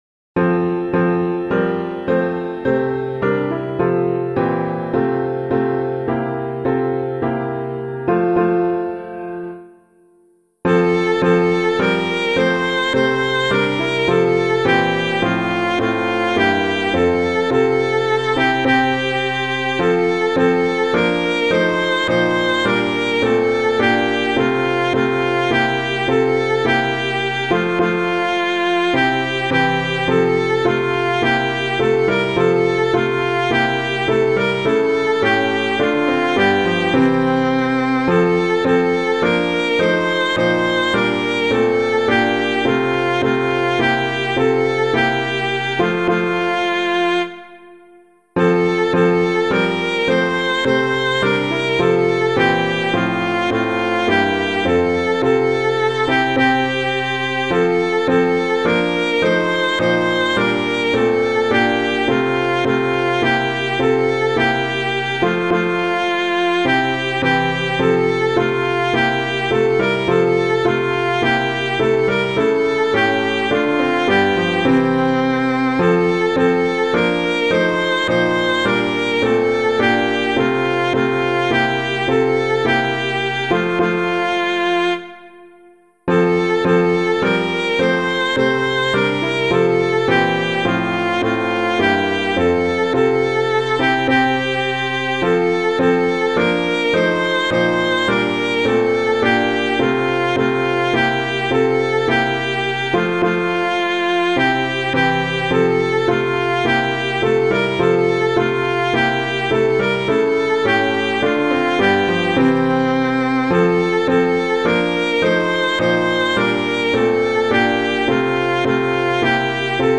Public domain hymn suitable for Catholic liturgy.
Sing with All the Saints in Glory [Irons - HYMN TO JOY] - piano.mp3